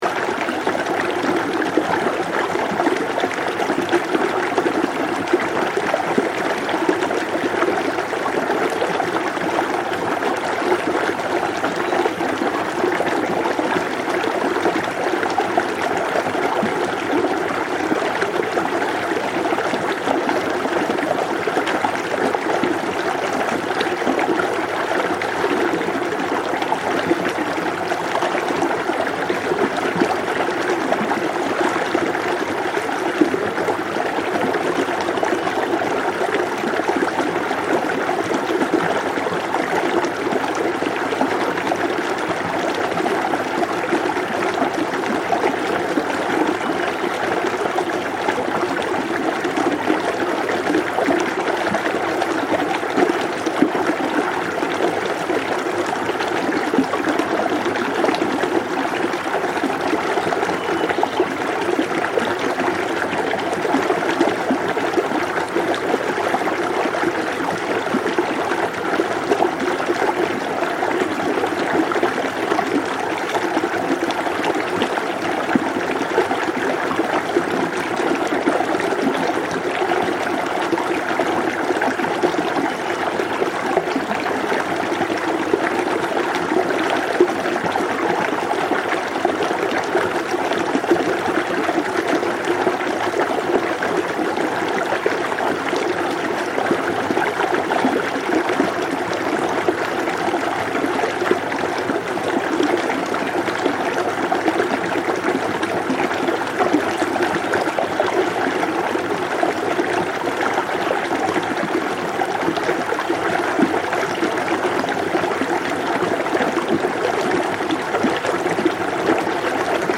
High in the misty mountains of northern Vietnam, the sound of water guides you to serenity. A gentle, steady stream cascades from the Love Waterfall as birds call softly in the distance – their contrast providing the perfect melody.
This tranquil soundscape captures the pure essence of Sa Pa’s beauty, where nature sings in tones of peace and clarity, inviting you to breathe deeply and let go.